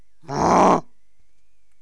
bull_ack2.wav